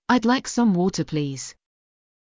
イギリス英語では母音に挟まれた”t”を強めに発音します。
イギリス英語：I’d like some water, please.
BE-water1.mp3